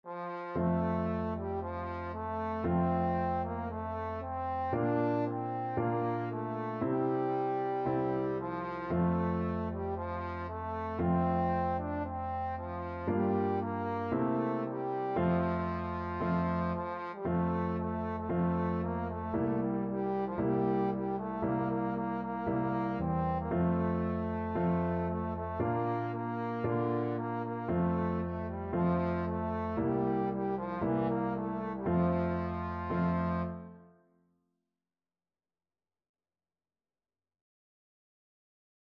Trombone
F major (Sounding Pitch) (View more F major Music for Trombone )
4/4 (View more 4/4 Music)
Traditional (View more Traditional Trombone Music)